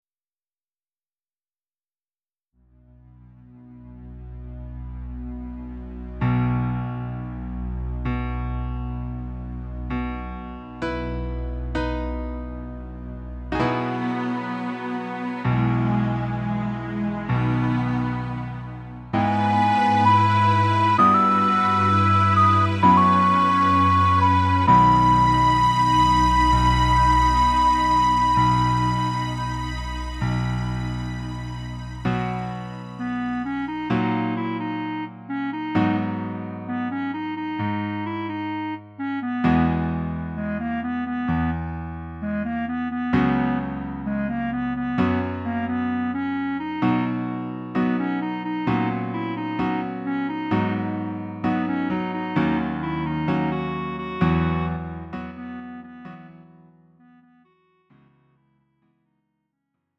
음정 -1키 4:31
장르 가요 구분 Lite MR